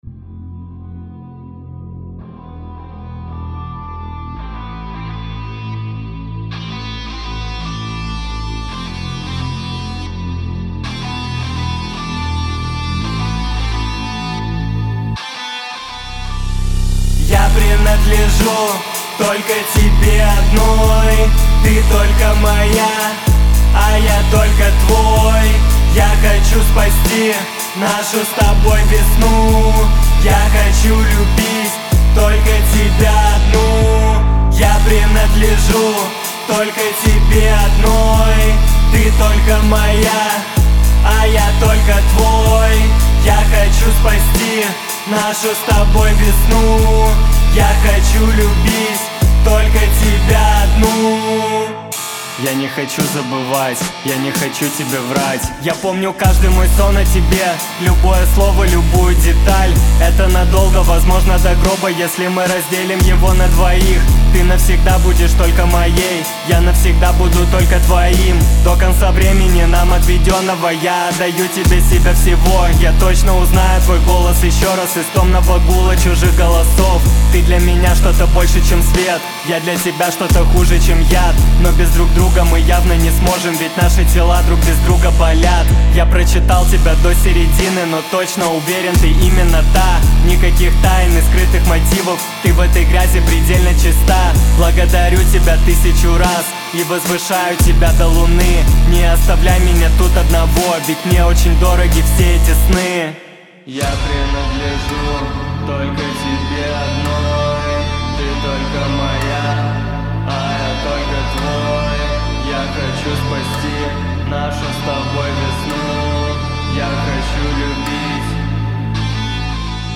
Категории: Русские песни, Рэп и хип-хоп.